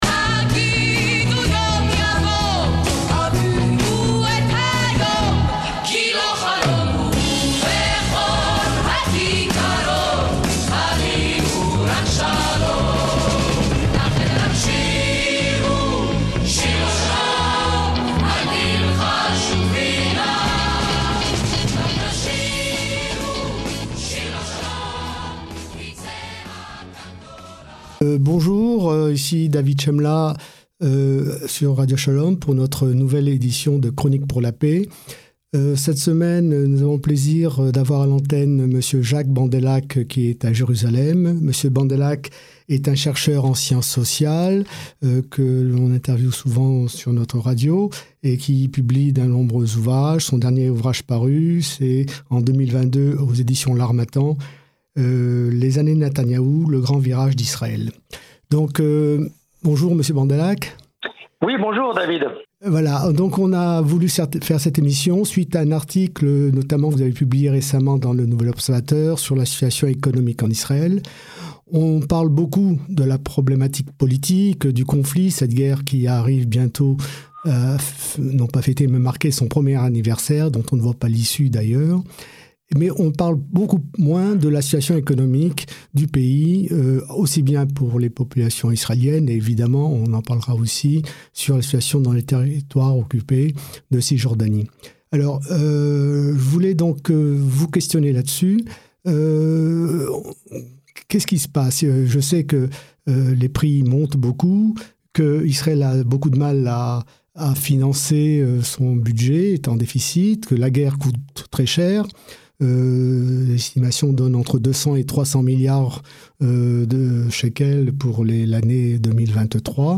Dans le cadre de Chroniques pour la Paix, émission bimensuelle parrainée par La Paix Maintenant et JCall sur Radio Shalom